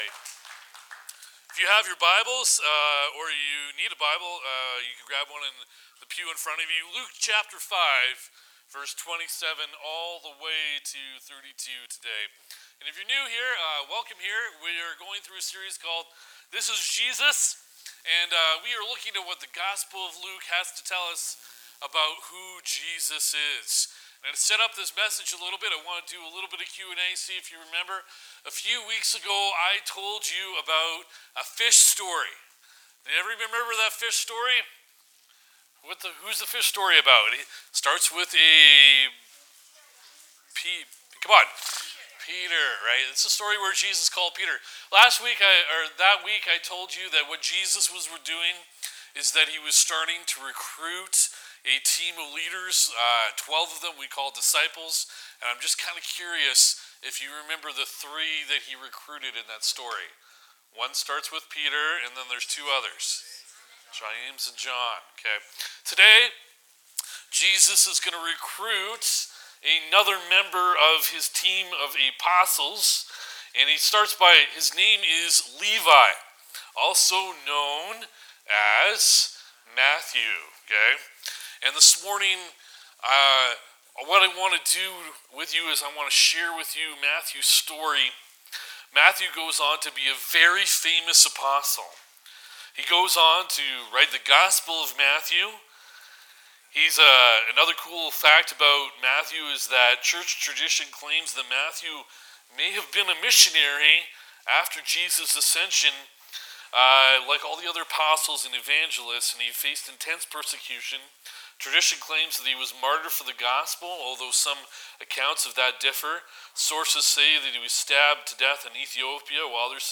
March 15 Sermon